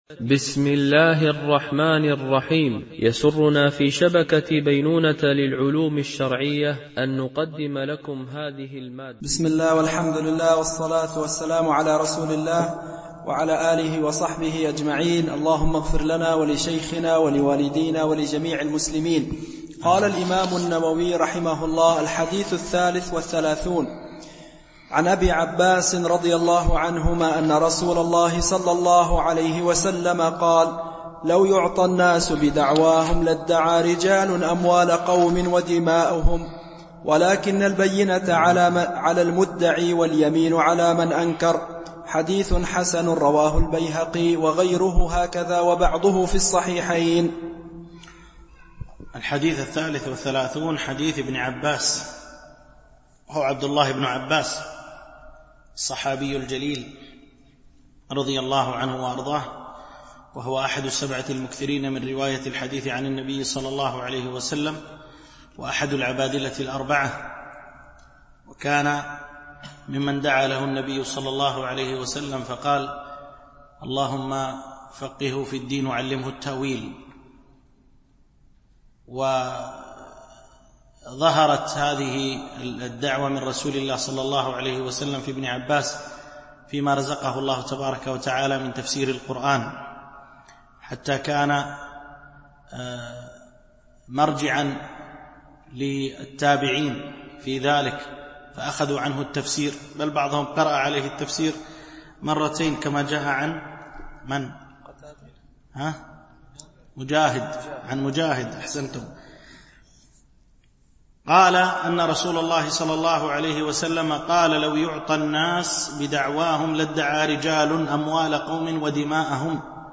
شرح الأربعين النووية - الدرس 17 (الحديث 33 - 35)